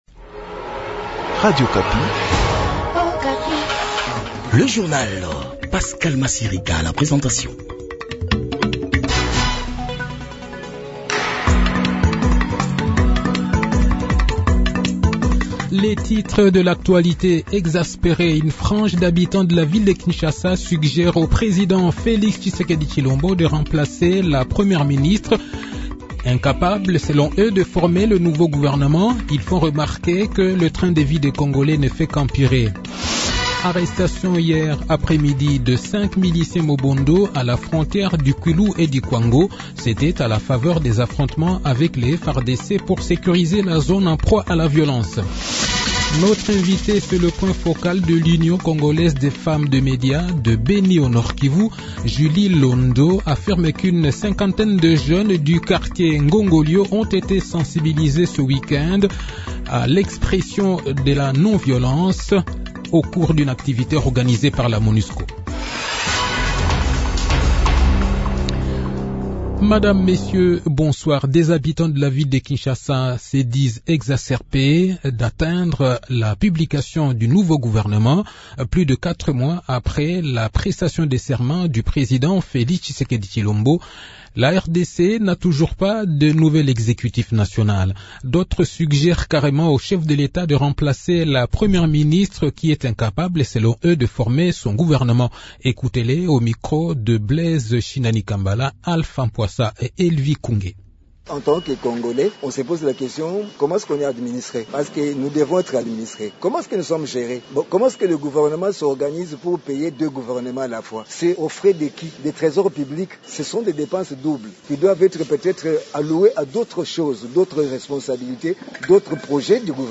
Le journal de 18 h, 21 mai 2024